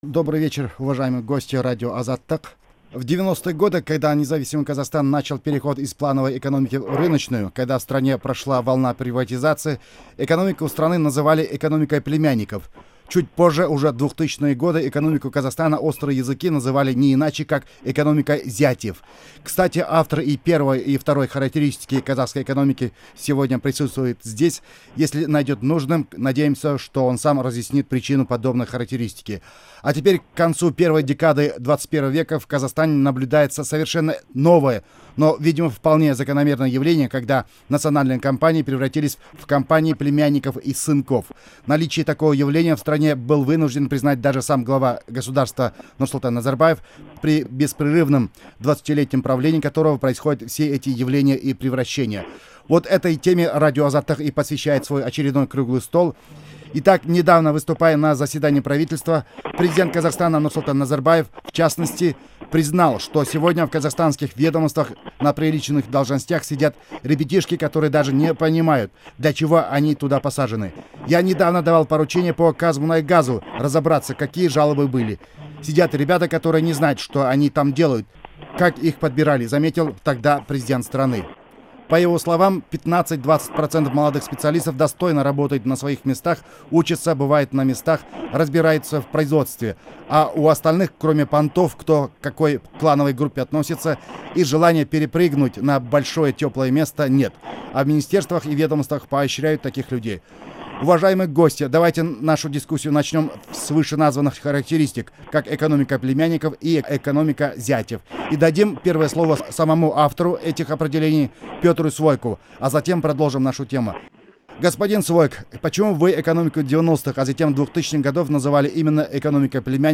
Аудиозапись круглого стола. Первая часть